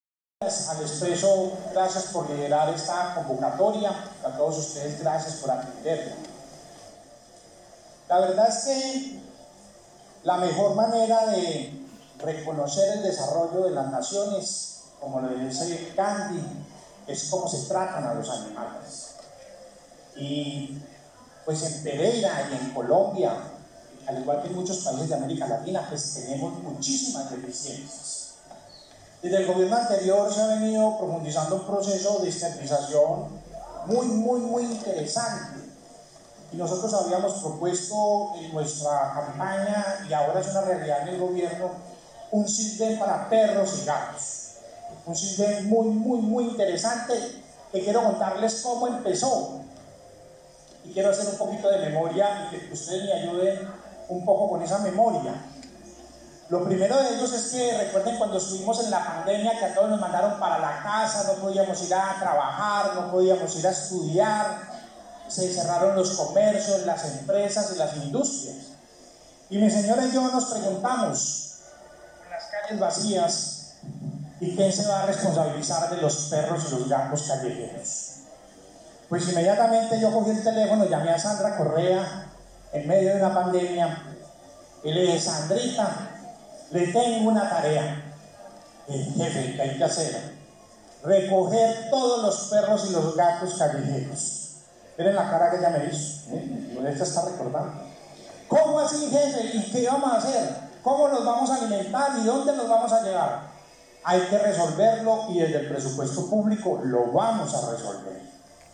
ALCALDE_CARLOS_MAYA.04.mp3